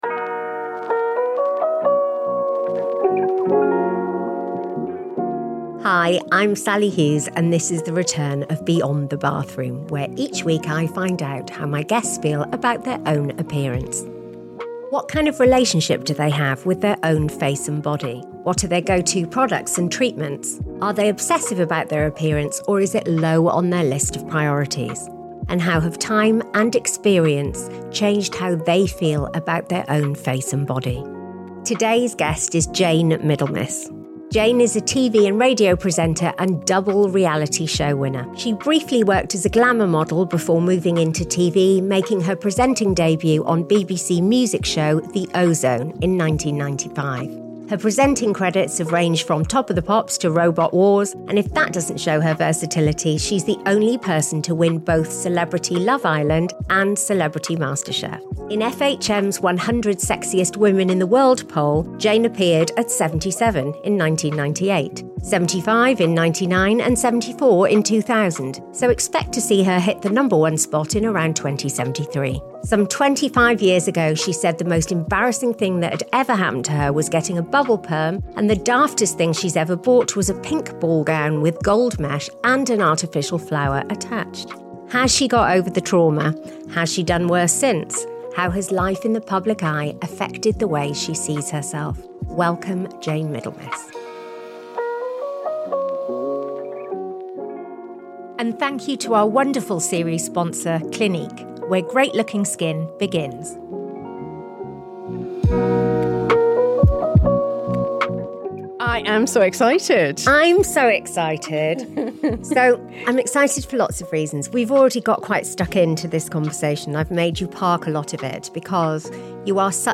Join for a candid conversation where Jayne reflects on her career in the public eye, from featuring on Page Three at just 19 to to winning multiple reality TV shows. Jayne reflects on how those experiences impacted her confidence and relationship with her body.